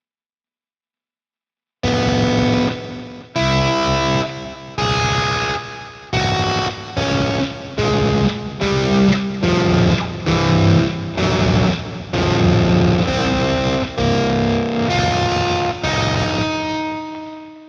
elektro gitardan robotik ses çıkması(dip ses değil)
Merhabalar öncelikle daha yeni ses kartı aldım ve guitar rigte çalarken aniden robotik seslerin başladığını ve yine aniden gittiğini farkettim.bunun üzerine...